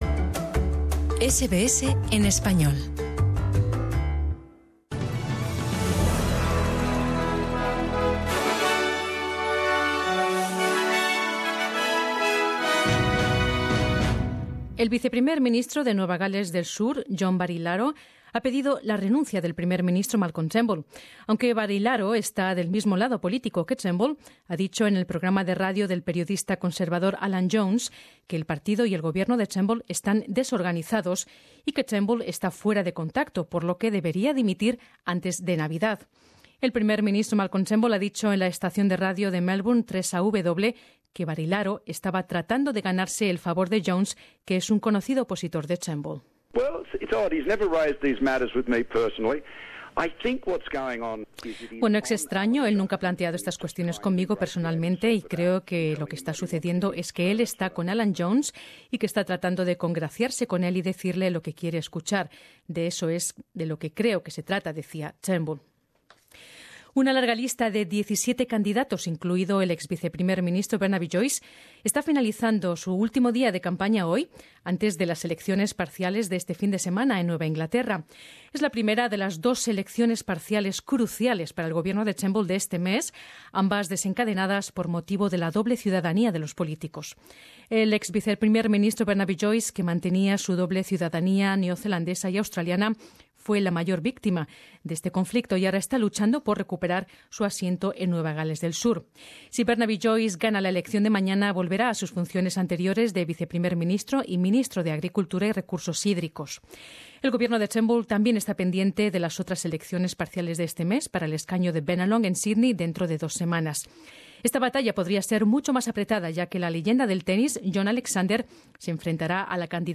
Extracto del boletín de noticias de SBS en español